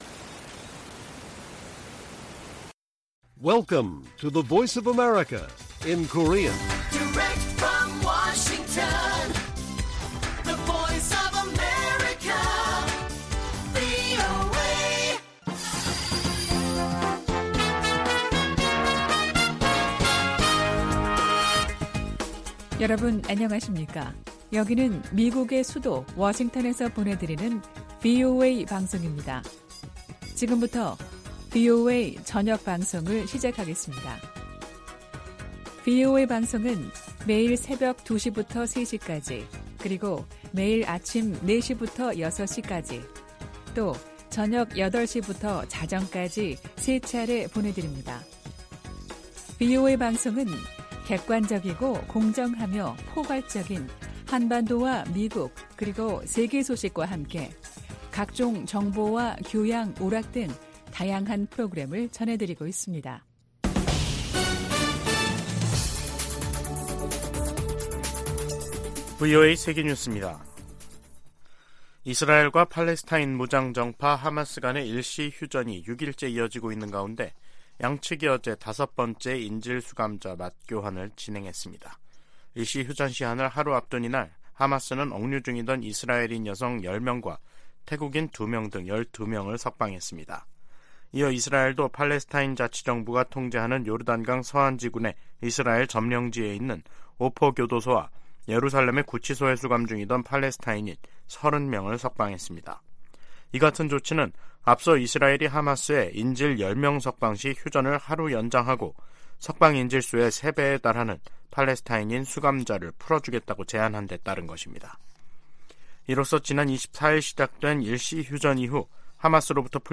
VOA 한국어 간판 뉴스 프로그램 '뉴스 투데이', 2023년 11월 29일 1부 방송입니다. 미국 정부는 북한의 정찰위성 발사를 규탄하면서 면밀히 평가하고, 러시아와의 협력 진전 상황도 주시하고 있다고 밝혔습니다. 미 국무부는 북한이 비무장지대(DMZ) 내 최전방 감시초소(GP)에 병력과 장비를 다시 투입한 데 대해 긴장을 부추기고 있다고 비판했습니다. 북한 정찰위성은 고화질 사진을 찍을 수 없다고 전문가들이 평가했습니다.